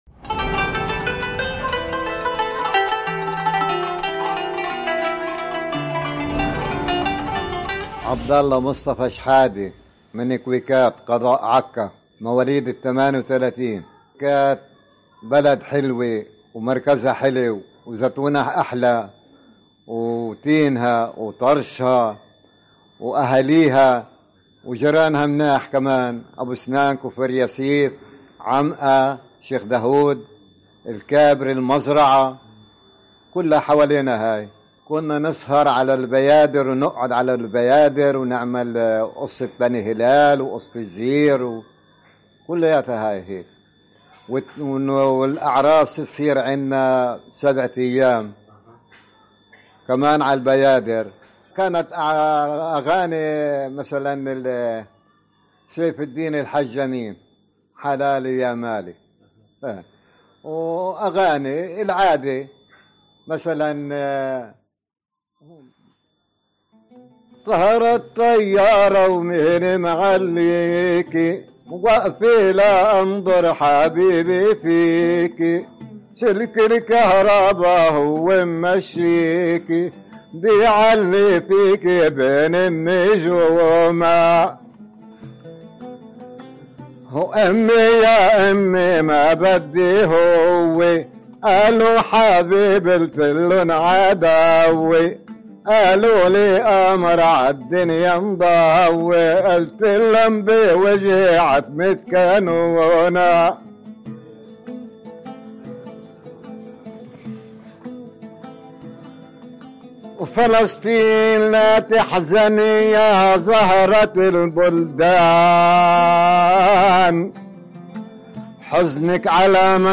المقابلة